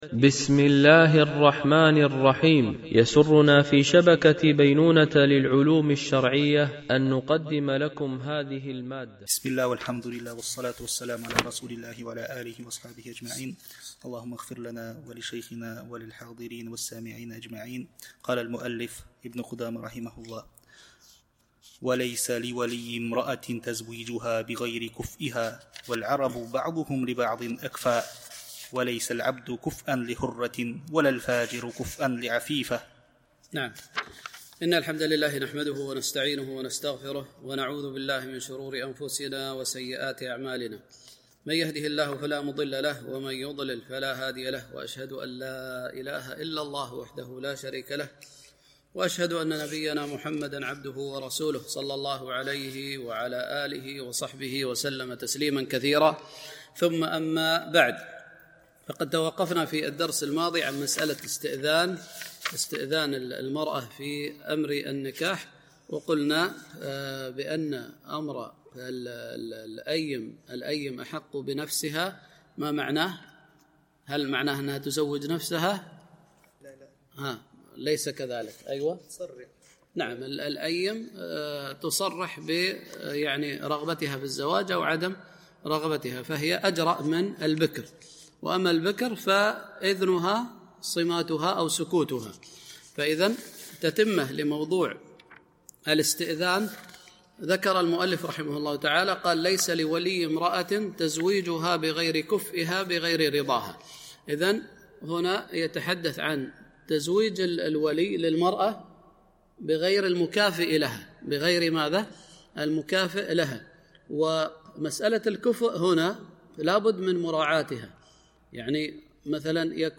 شرح عمدة الفقه ـ الدرس 116 (كتاب النكاح)